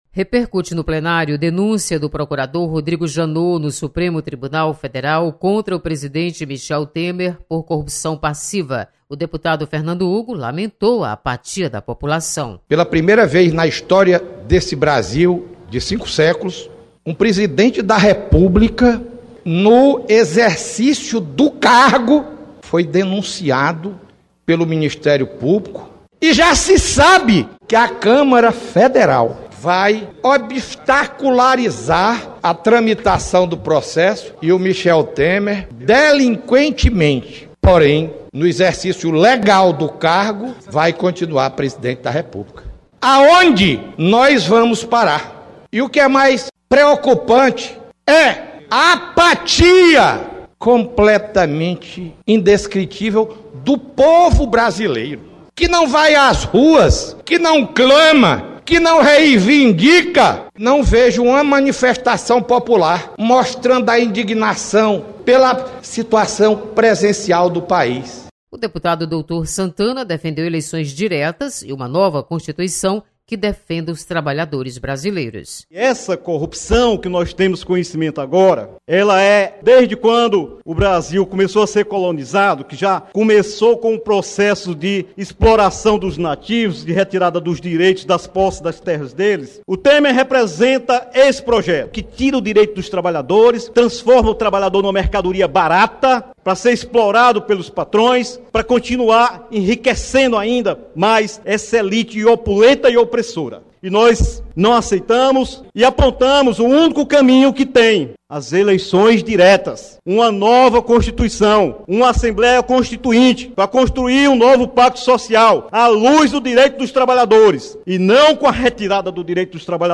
Deputados comentam denúncia contra Michel Temer. Repórter